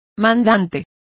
Complete with pronunciation of the translation of principal.